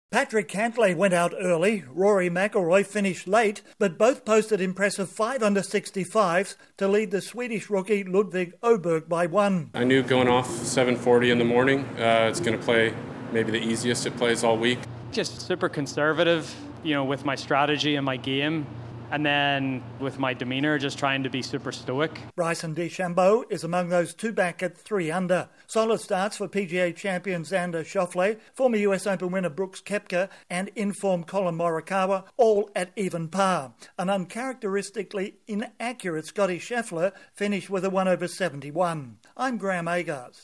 There is a two-way tie on top of the leaderboard after the first round of the US Open golf championship in Pinehurst, North Carolina. Correspondent